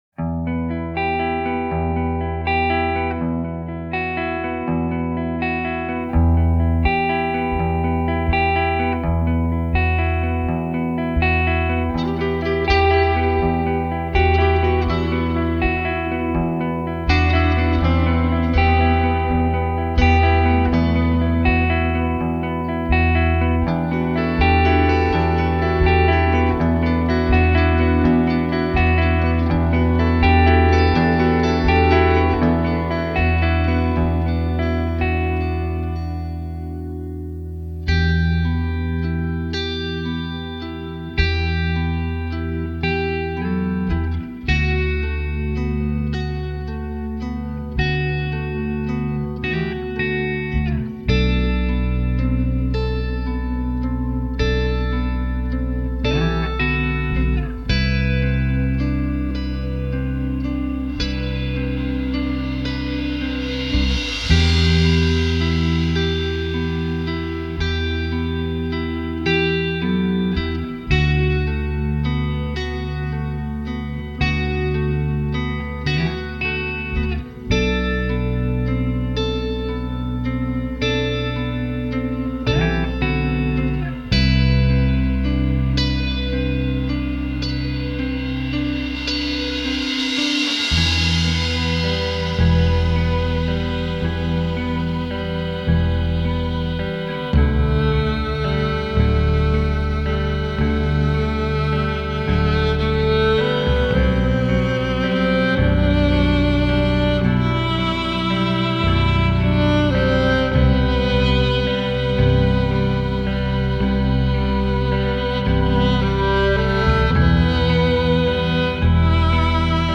Post-rock
موسیقی بیکلام